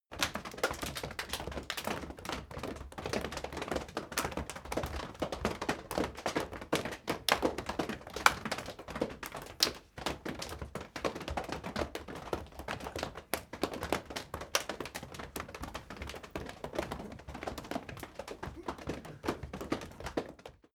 Royalty free sounds: Body sounds